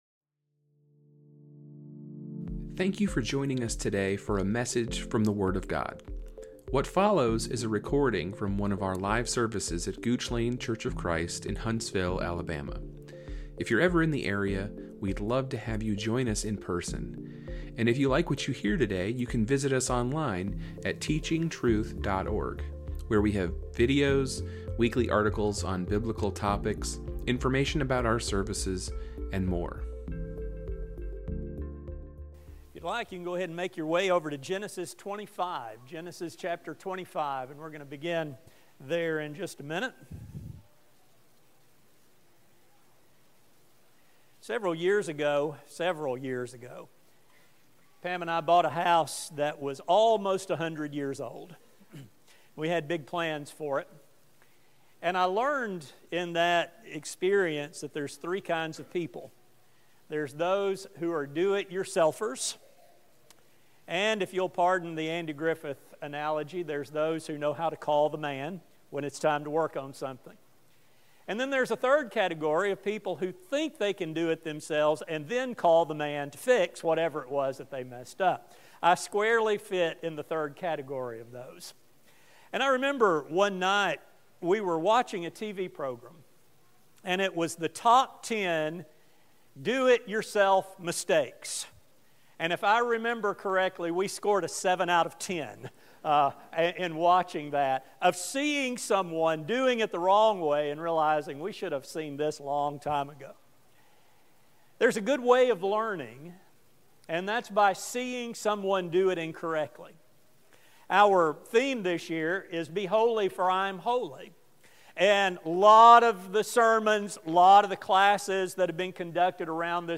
This study will focus on the shortsightedness demonstrated by this man of old and how modern Christians can fall into the same kinds of mistakes he made. A sermon